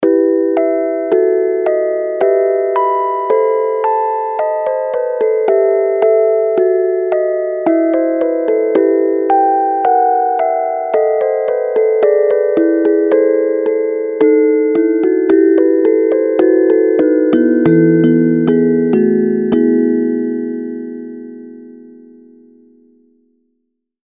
切なさより悲しみ成分強め（当社比）のオルゴール曲です